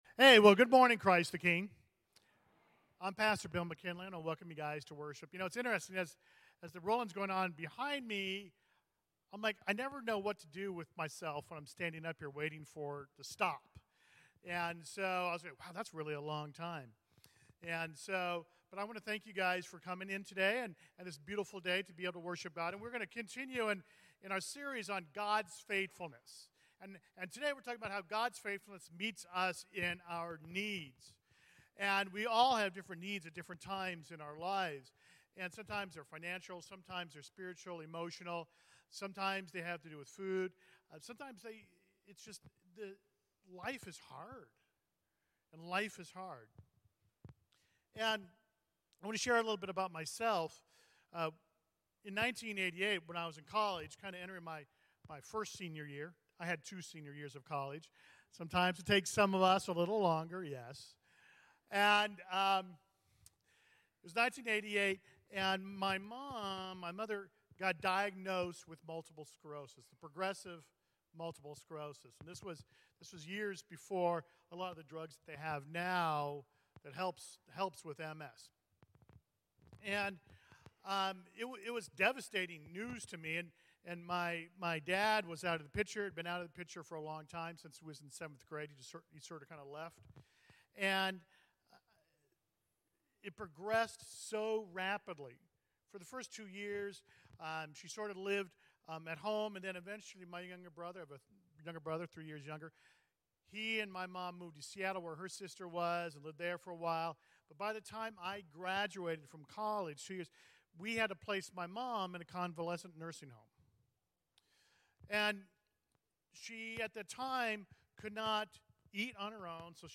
CTK-Clipped-Sermon.mp3